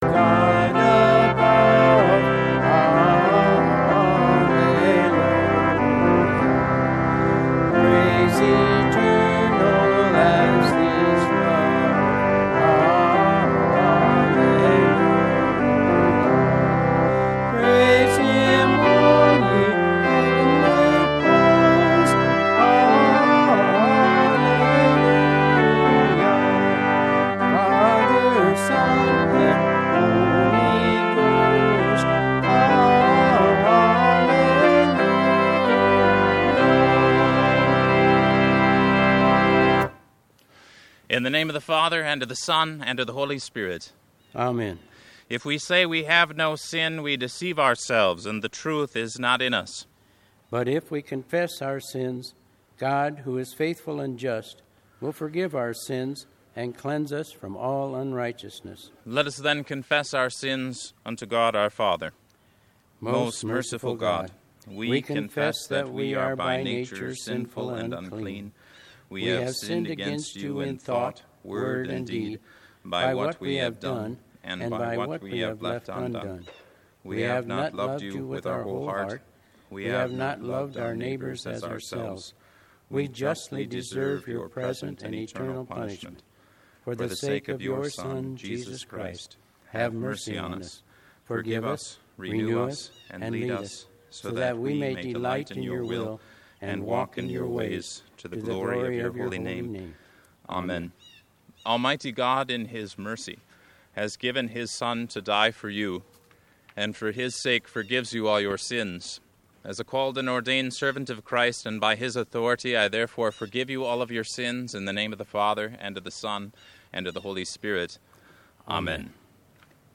200412 Easter Drive in Service